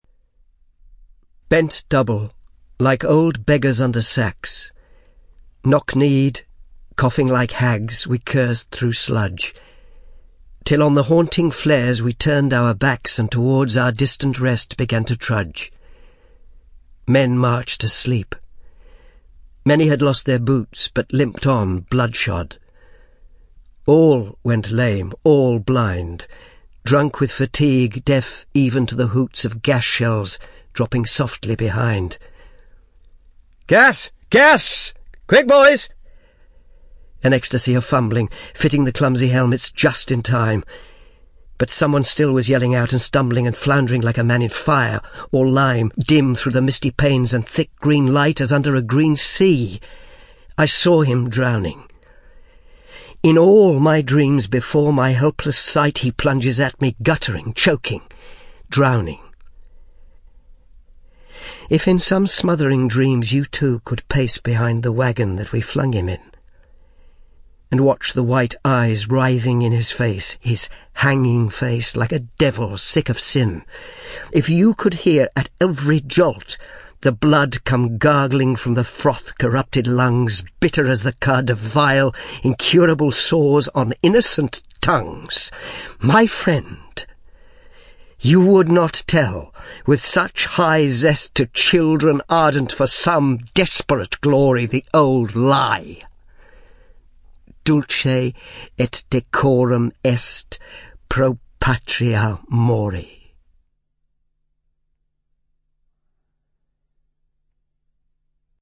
安东·莱塞（Anton Lesser）朗读《为国捐躯》。